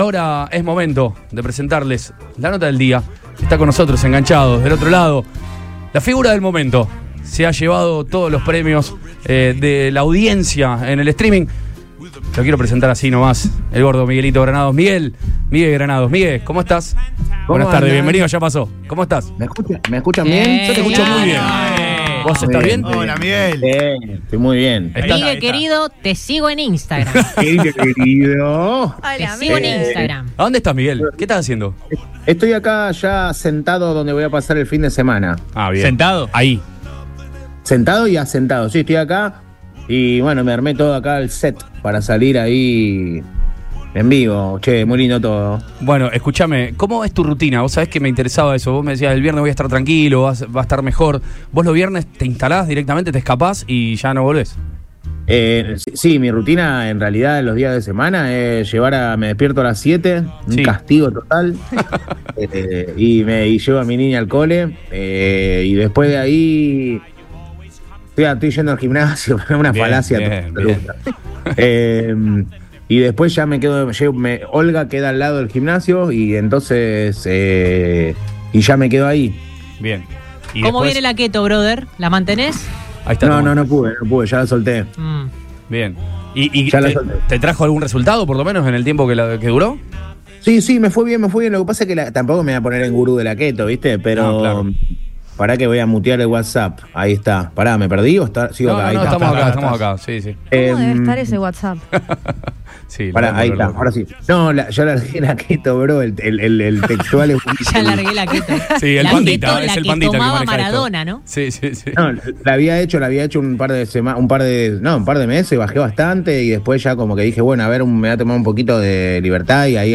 Miguel Granados estuvo charlando con el equipo de Ya Pasó de su exitoso presente. El conductor de Olga habló de diferentes tópicos acompañado del humor que lo caracteriza. Desde su fanatismo por las motos hasta los detalles de su entrevista con Lionel Messi, Migue compartió todos los detalles de su cotidianeidad.